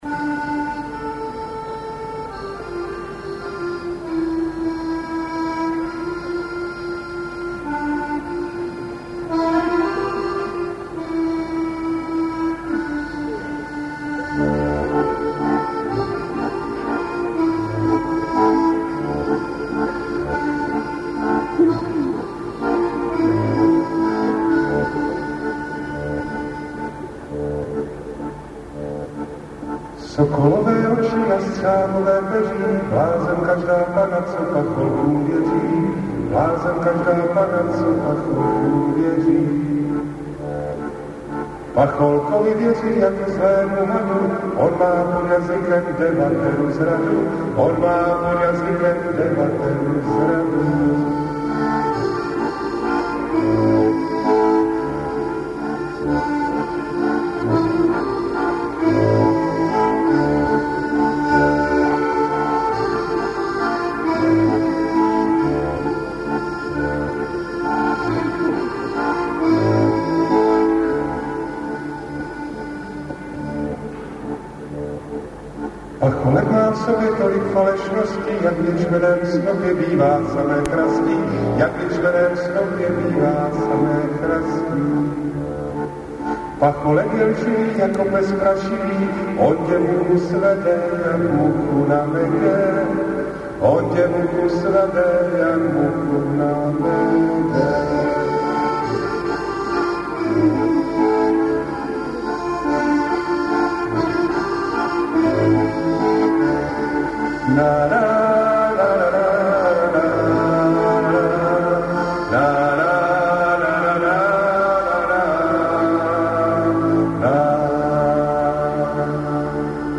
Lidové písně zpívané | Jarek Nohavica